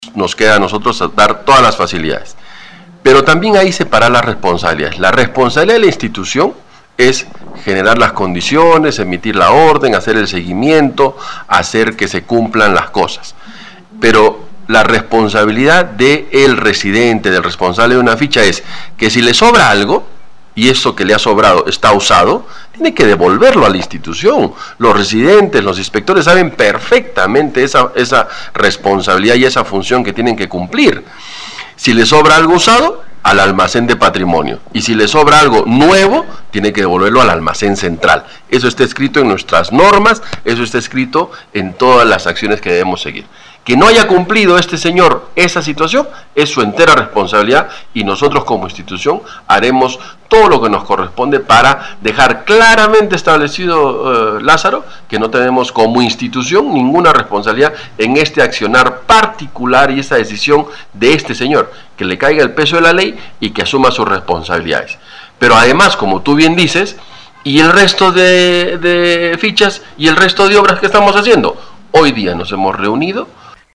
Al respecto, el gerente destacó en conversación con Radio Uno este material debe ser devuelto a instalaciones regionales no destinarlos a otros usos.